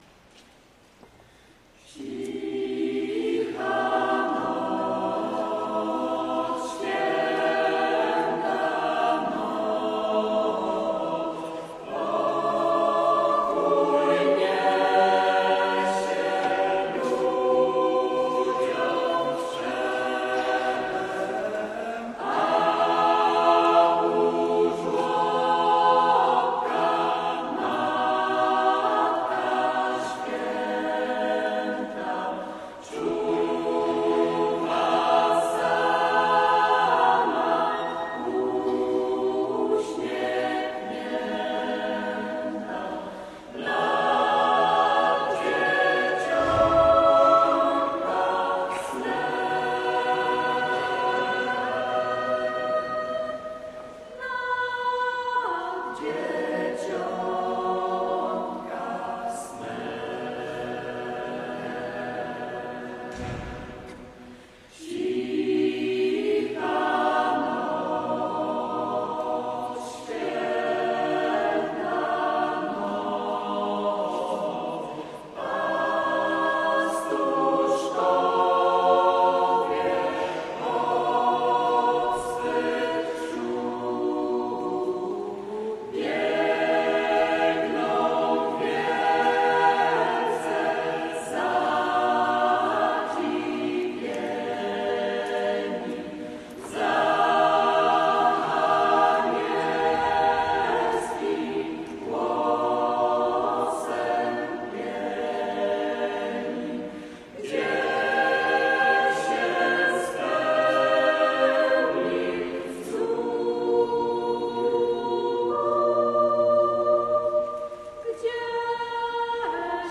BENEDICTUS – Lubelski Festiwal Chórów Parafialnych
Chór kameralny „Benedictus” parafii pw.
Obecnie chór liczy 17 osób.